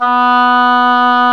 WND OBOE3 B3.wav